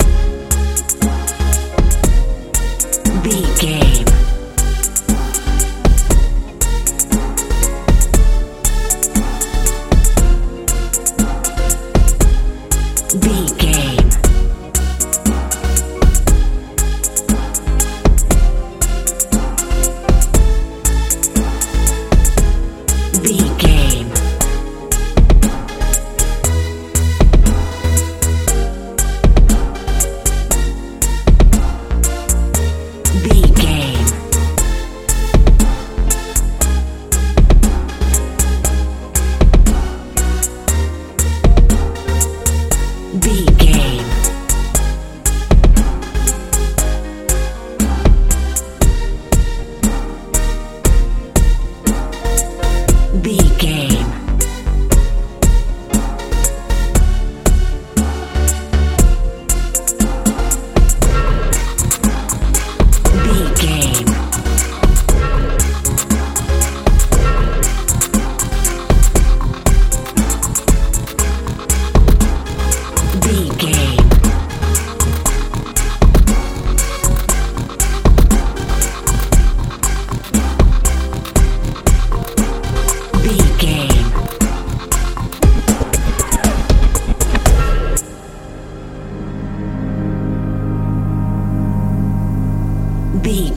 euro dance feel
Ionian/Major
C♯
bright
synthesiser
bass guitar
drums
80s
90s
anxious